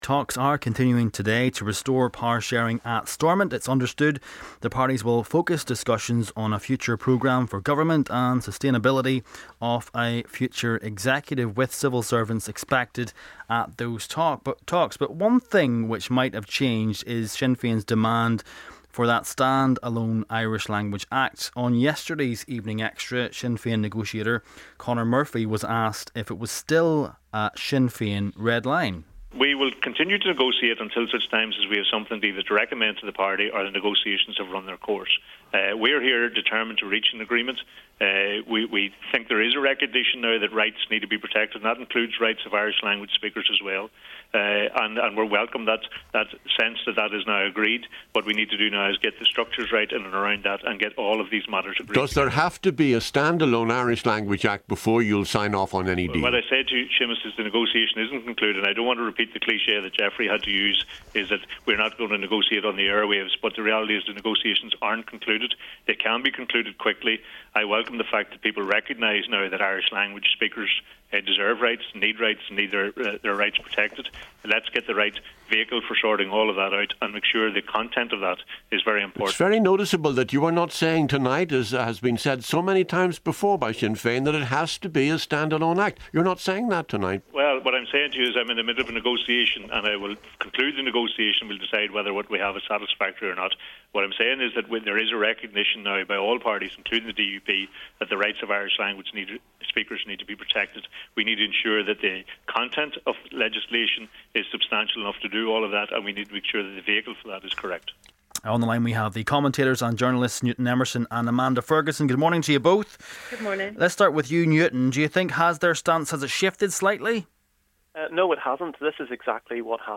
On yesterday's Evening Extra Sinn Fein negotiator Conor Murphy was asked if it was still a Sinn Fein red line.